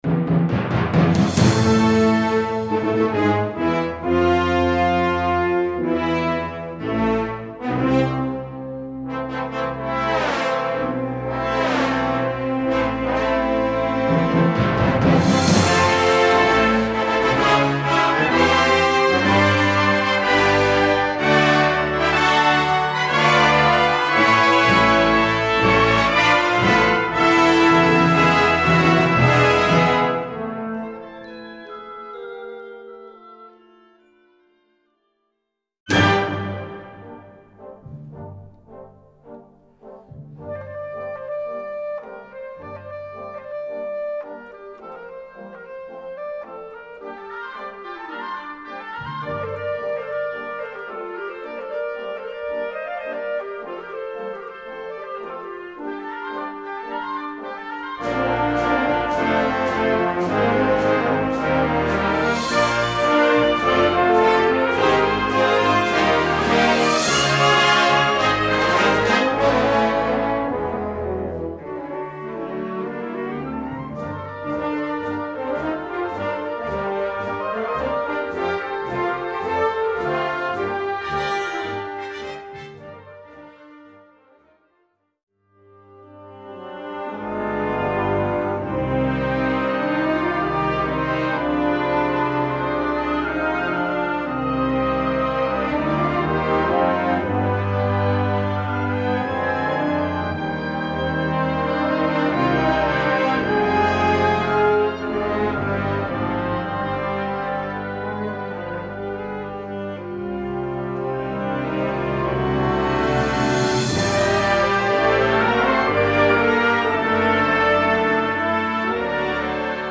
Répertoire pour Harmonie/fanfare - Concert Band ou Harmonie